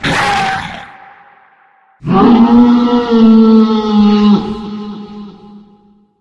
sounds / monsters / lurker / hit_2.ogg